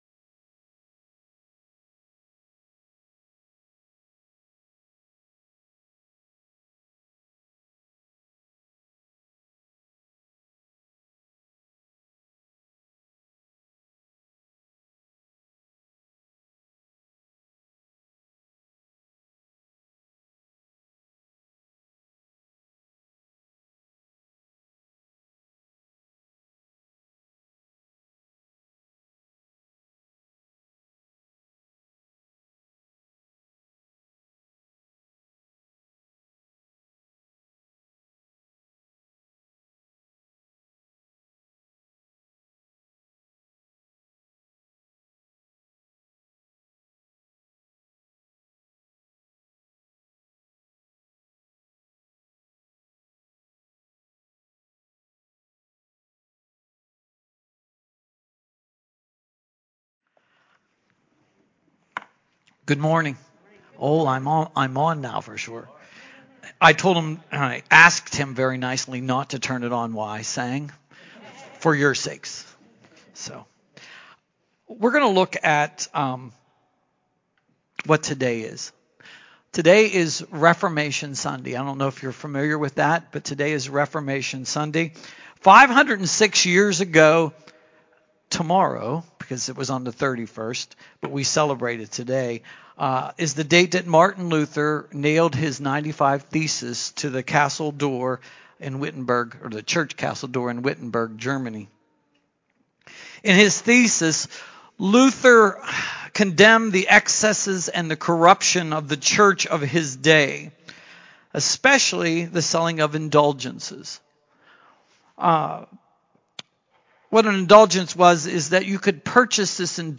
Motivated Love Sermon